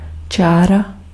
Ääntäminen
Synonyymit letter note row epistle (geometria) line segment (geometria) straight line queue string game edge series reinforce lineup Ääntäminen : IPA : /laɪn/ US : IPA : [laɪn] Lyhenteet ja supistumat (laki) l.